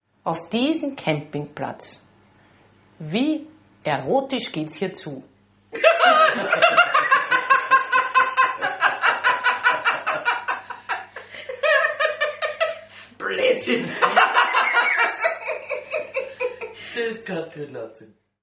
Hier ein Stück Originalton aus der Sendung Am Schauplatz, die den Titel "Zuhause ist überall" trug (19.10.2017).